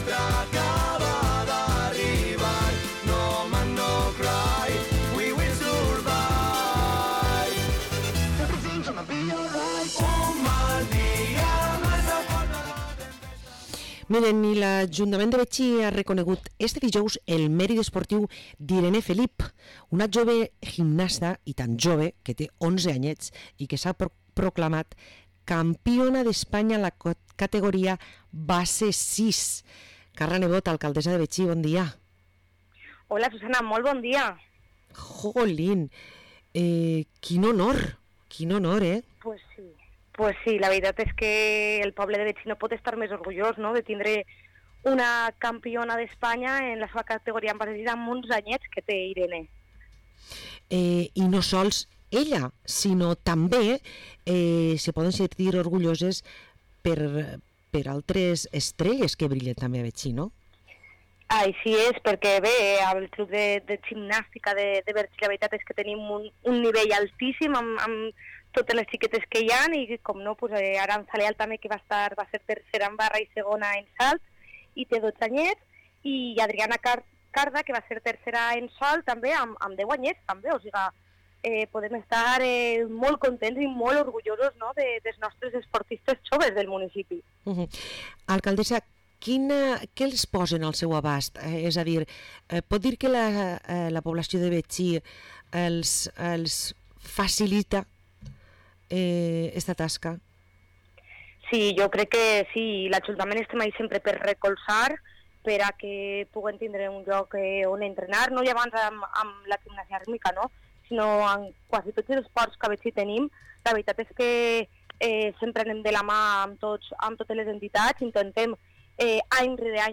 Parlem amb l´alcaldessa de Betxí, Carla Nebot